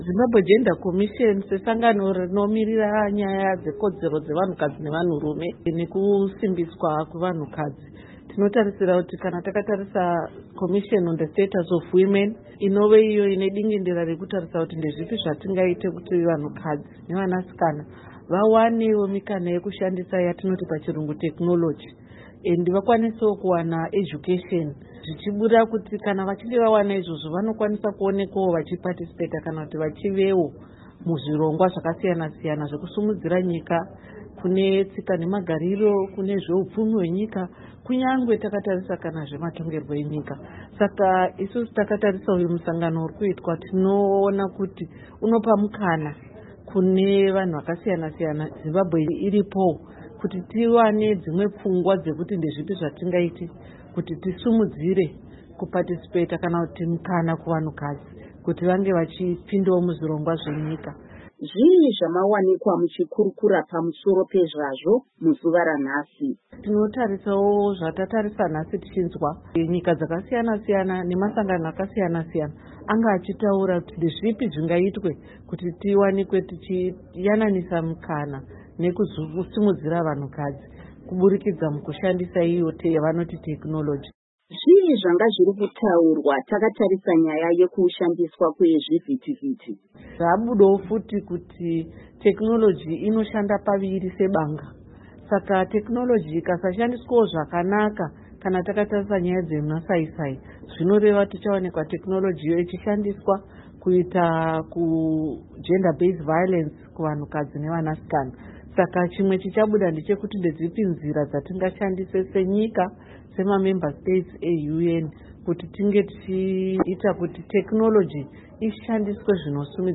Hurukuro naAmai Virginia Muwanigwa